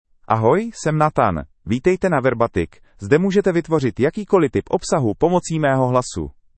MaleCzech (Czech Republic)
NathanMale Czech AI voice
Nathan is a male AI voice for Czech (Czech Republic).
Voice sample
Listen to Nathan's male Czech voice.
Male